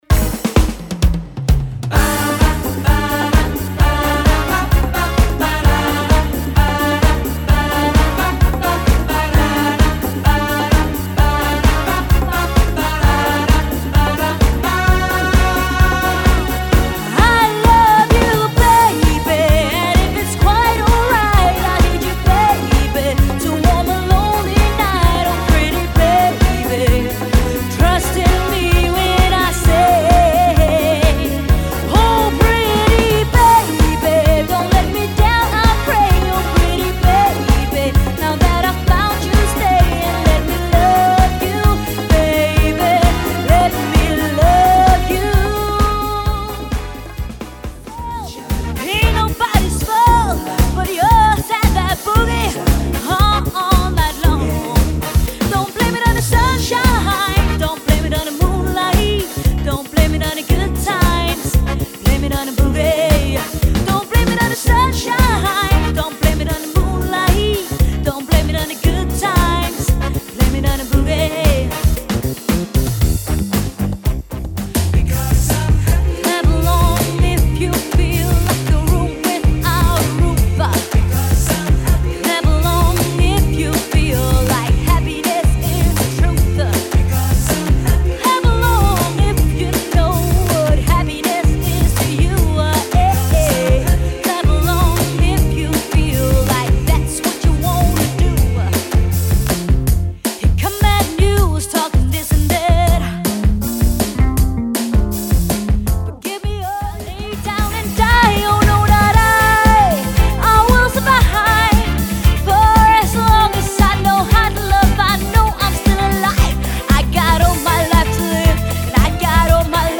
• Allround Partyband